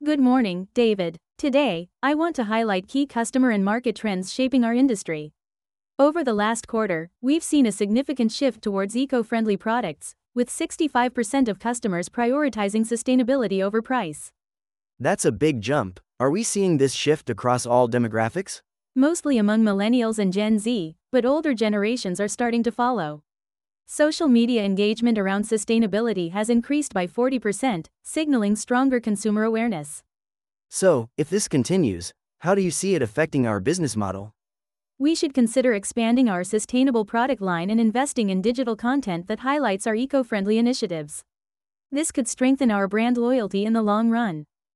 レッスンでは、以下のような会話を聞いた後に、講師と内容についてディスカッションをします。